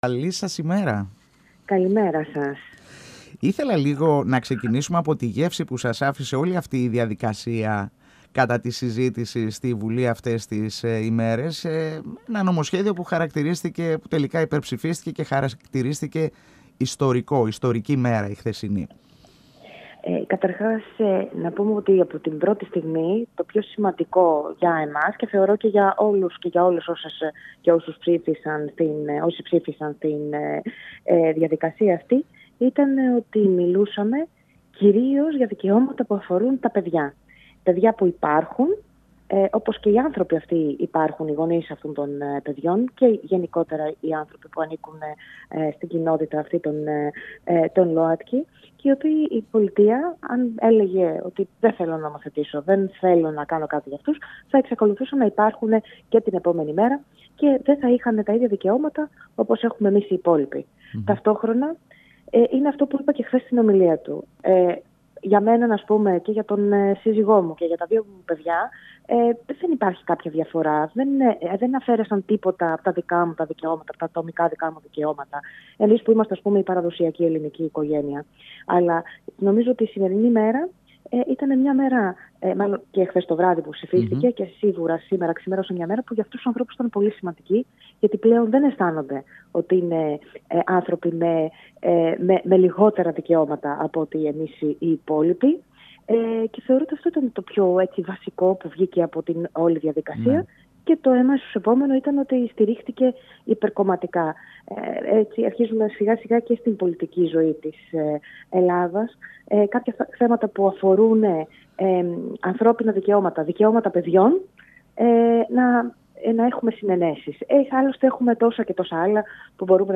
H Eισηγήτρια του νομοσχεδίου για την ισότητα στο γάμο, τέως υφυπουργός Εργασίας και Κοινωνικών Υποθέσεων, αρμόδια για θέματα Δημογραφικής Πολιτικής και Οικογένειας Μαρία Συρεγγέλα μιλώντας στην εκπομπή «Εδώ και Τώρα» του 102FM της ΕΡΤ3 αναφέρθηκε στην επόμενη μέρα της υπερψήφισης του σχετικού νομοσχεδίου, που είναι πλέον νόμος.
Πάντα με πυξίδα τις παραδόσεις, τις αξίες μας, τον πυρήνα του Ελληνισμού – την οικογένεια». 102FM Εδω και Τωρα Συνεντεύξεις ΕΡΤ3